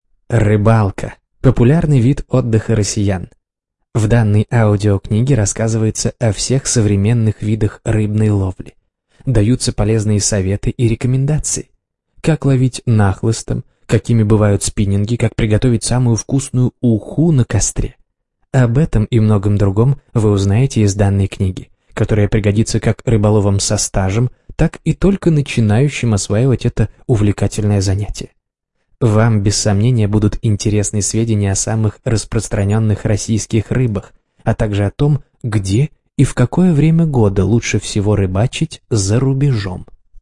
Аудиокнига Рыбалка на Руси. Все о рыбах и снастях | Библиотека аудиокниг